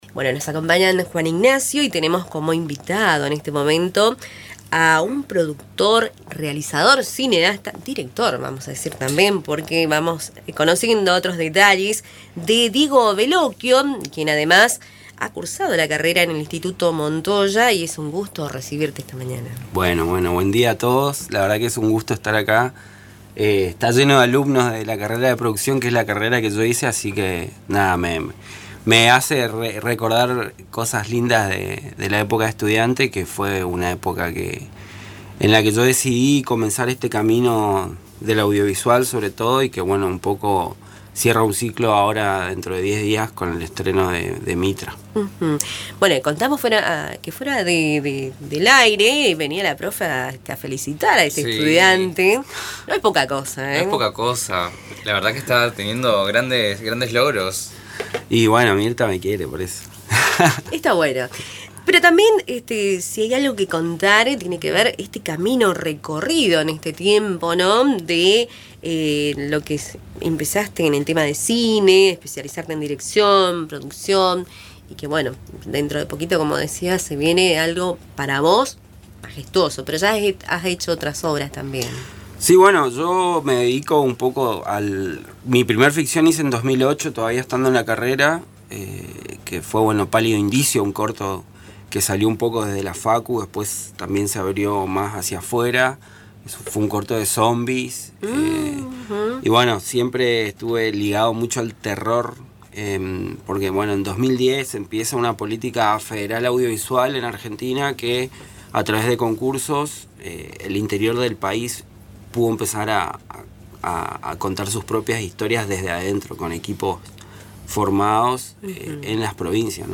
visitó los estudios de Radio Tupambaé para compartir su experiencia en el mundo del cine y anticipar el estreno de su nueva película, Mitra: Apaga la luz para poder ver, en el 40° Festival Internacional de Cine de Mar del Plata, uno de los más prestigiosos del mundo.